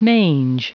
Vous êtes ici : Cours d'anglais > Outils | Audio/Vidéo > Lire un mot à haute voix > Lire le mot mange
Prononciation du mot : mange